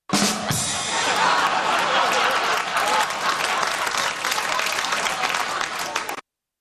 Rim Shot